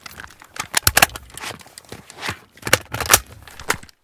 akm_reload.ogg